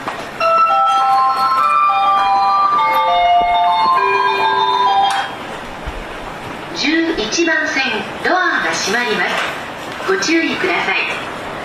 ただ、京成線・新幹線・宇都宮線・高崎線の走行音で発車メロディの収録は困難です。